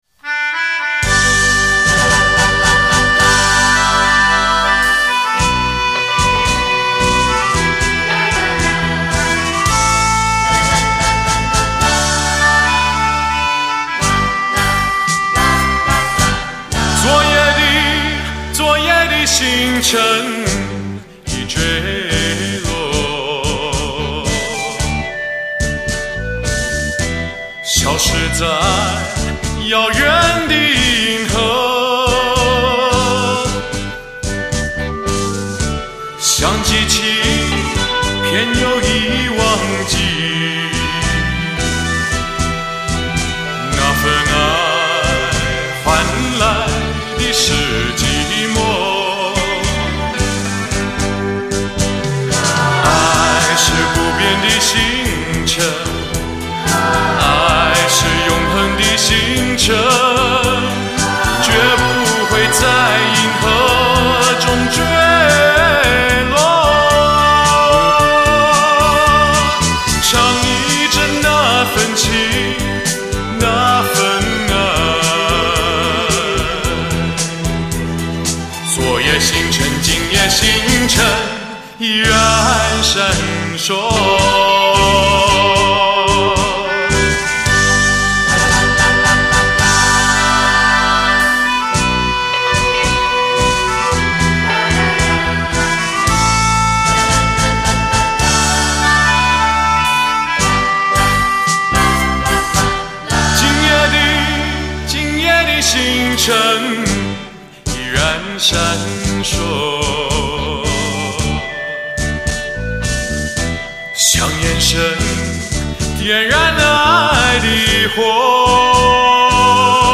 极具魅力的磁性嗓音，再次回荡在你我记忆里。